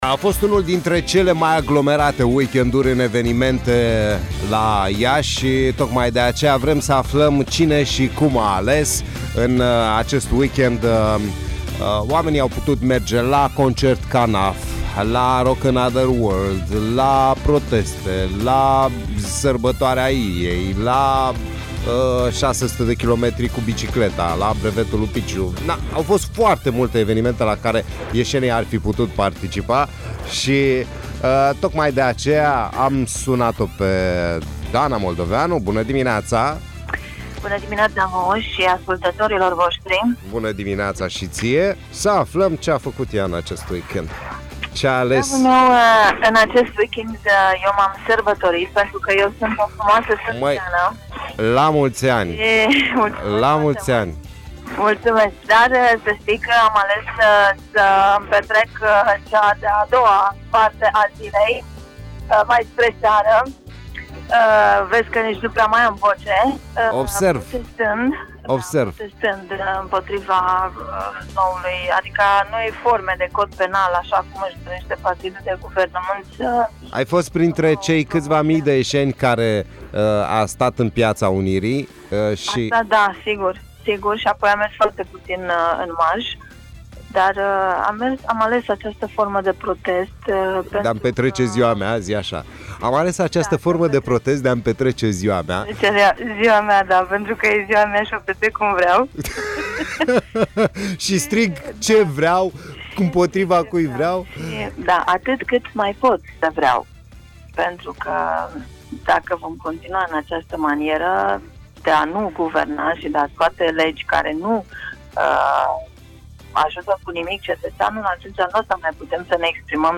Ascultă un interviu cu un om obișuit despre România de azi